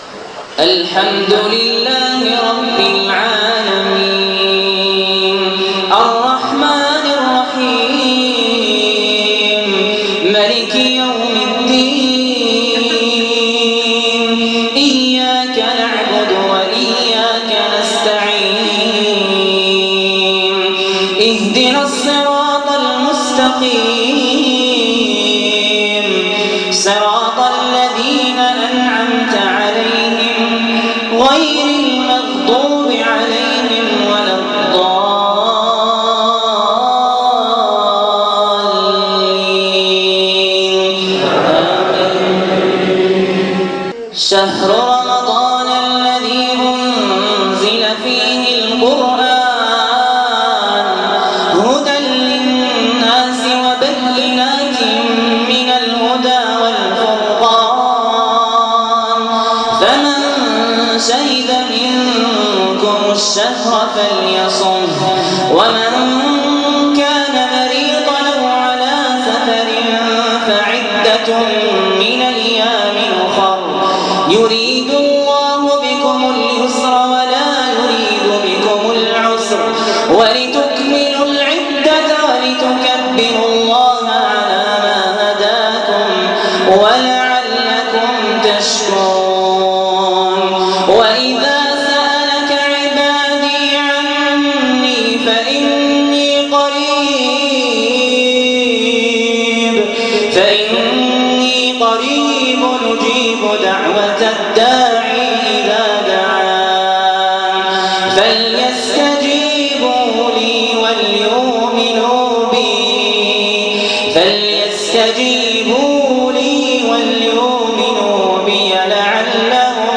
تلاوات مكررة تريح الاعصاب وتهدئ النفس تنسيك الدنيا وهمها